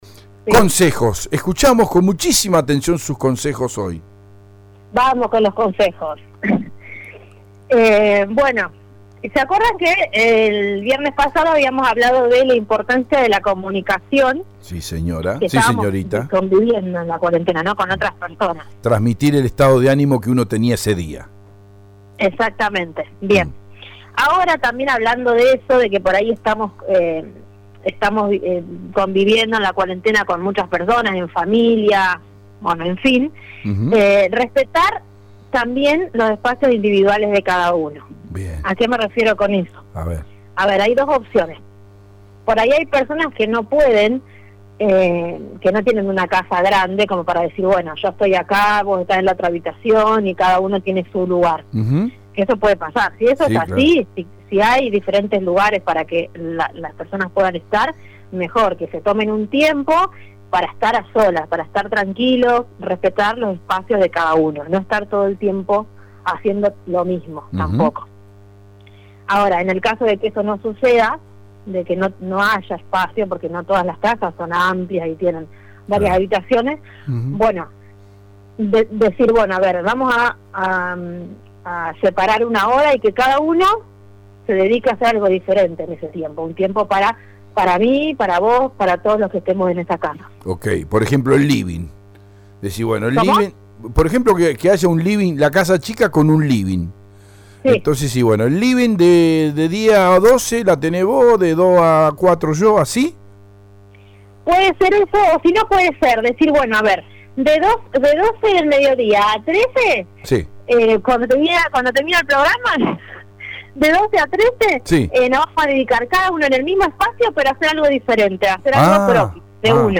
CON ZETA 1973: El resumen completo de los cuatro protagonistas del programa en radio EL DEBATE, del pasado viernes - EL DEBATE